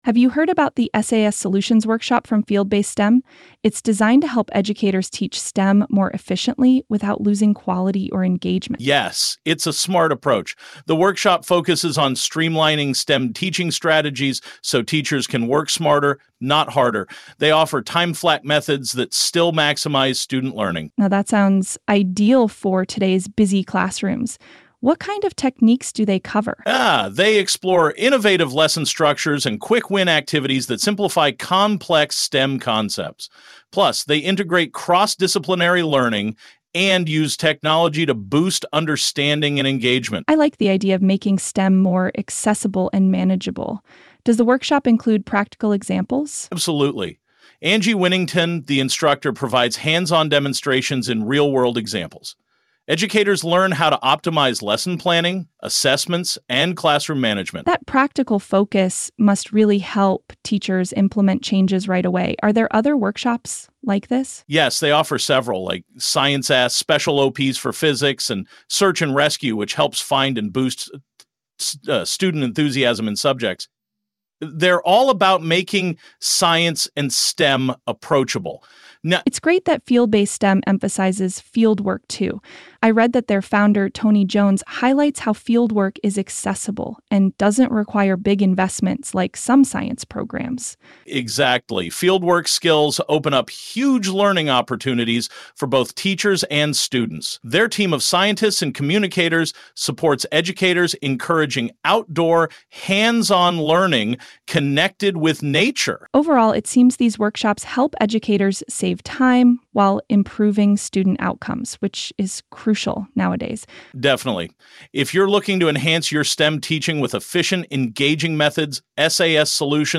CHECK OUT what AI says about this workshop: